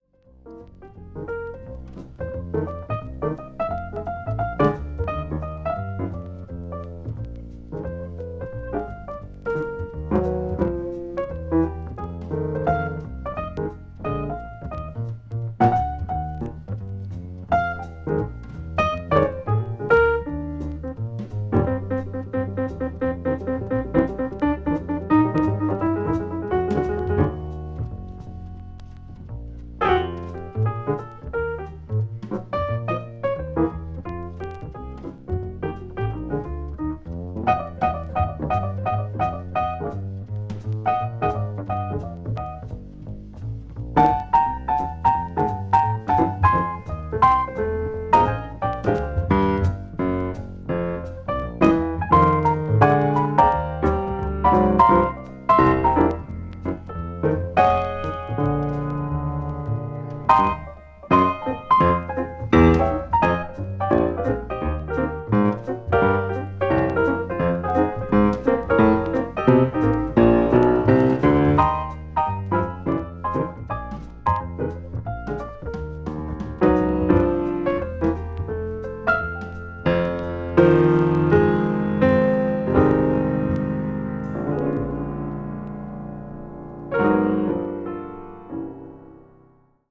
ベースとドラムはとても静かな演奏で
bop   cool jazz   modern jazz